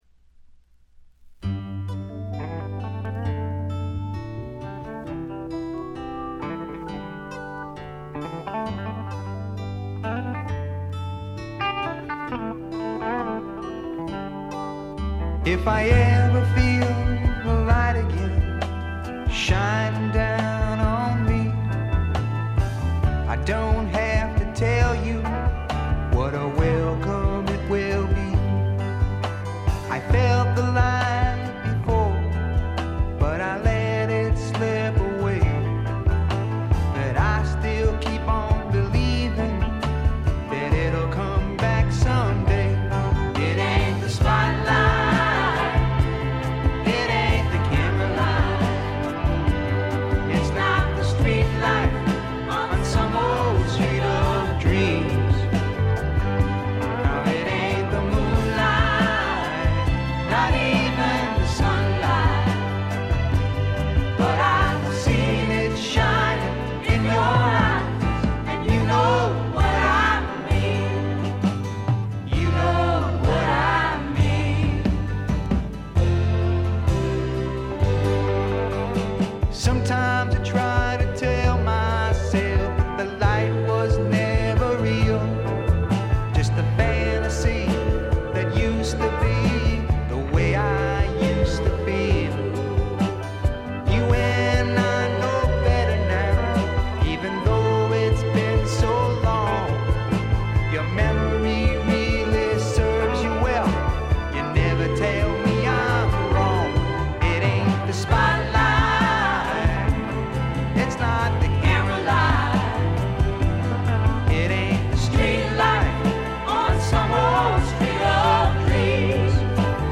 ほとんどノイズ感無し。
スワンプ系シンガーソングライター基本。
試聴曲は現品からの取り込み音源です。
Recorded at Muscle Shoals Sound Studios, Muscle Shoals, Ala.